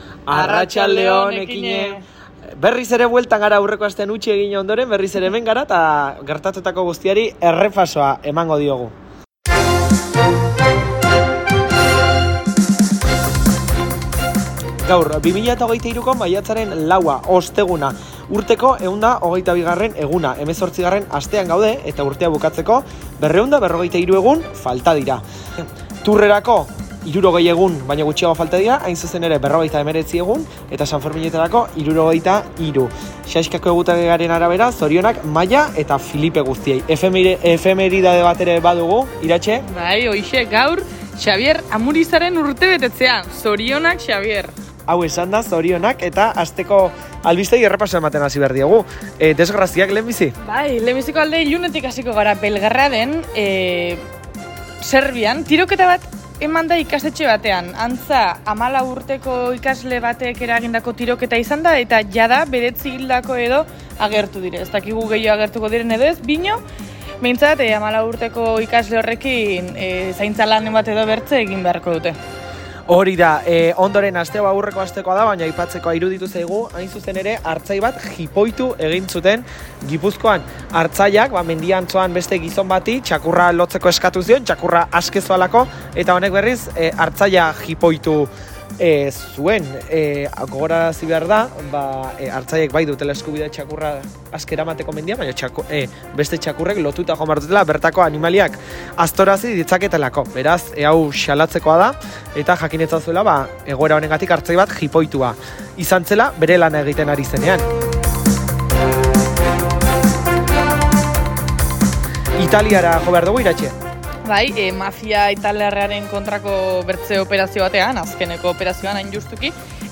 Gure tertulia 09.16